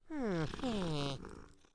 Npc Catpurr Sound Effect
npc-catpurr-4.mp3